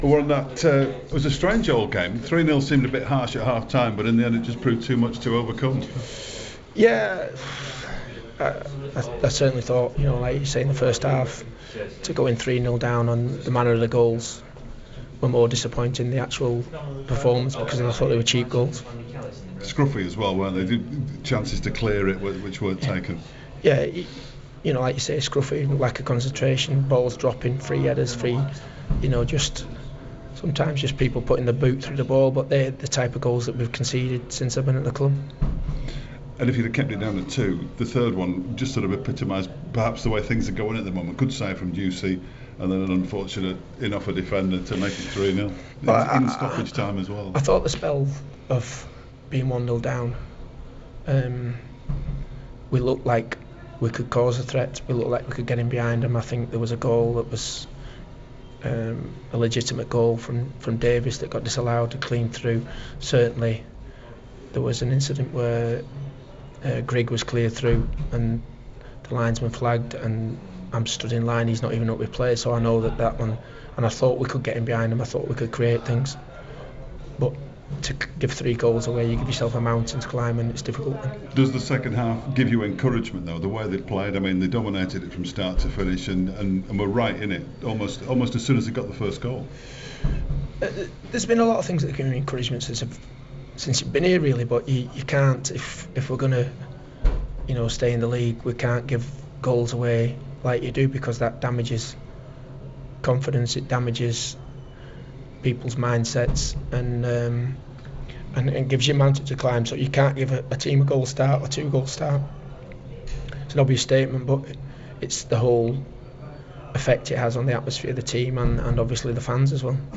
Wigan Athletic manager Warren Joyce speaks to the media following a 3-2 defeat away to Rotherham United in League One.